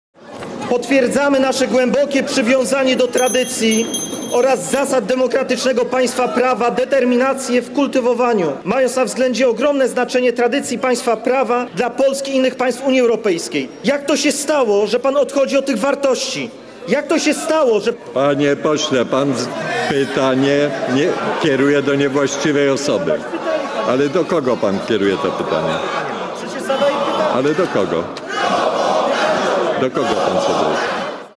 Brejza pytania nie dokończył, bo wicemarszałek Sejmu Ryszard Terlecki wyłączył mu mikrofon. Posłowie PiS zaczęli krzyczeć: „prowokator”.